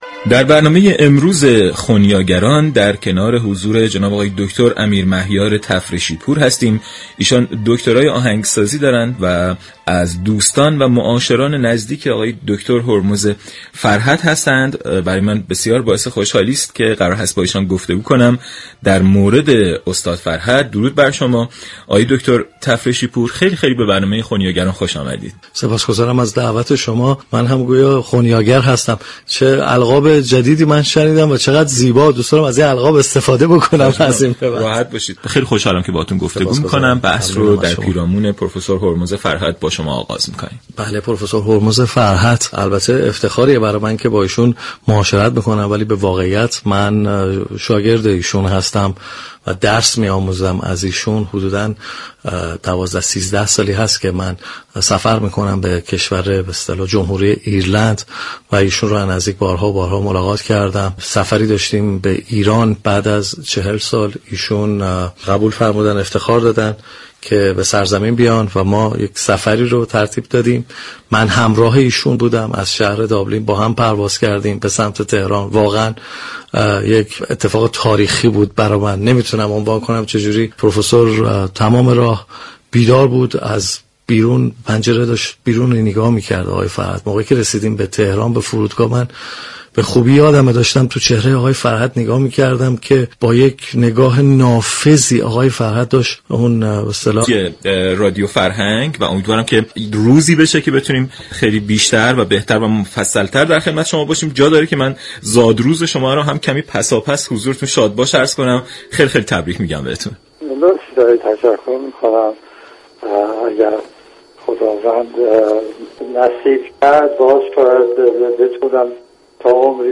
گفتگو با پرفسور هرمز فرهت در برنامه ی خنیاگران